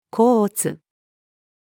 甲乙-female.mp3